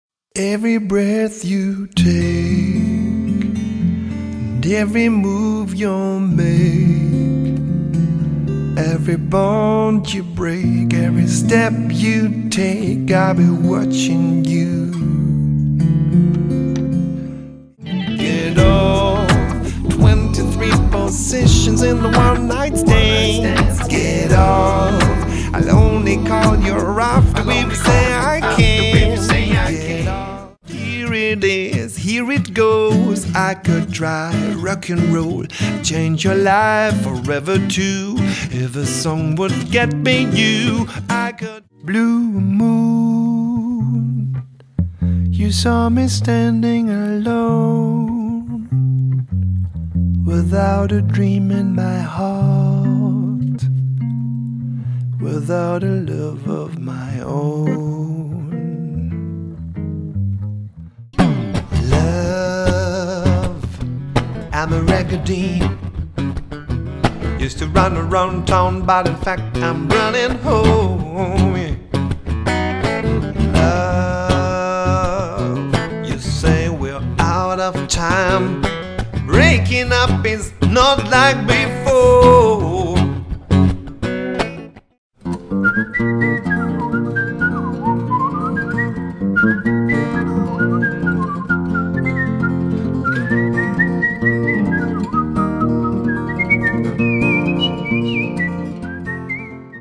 Zwei Musiker, zwei Looper - ein Orchester!
• Coverband
• Unplugged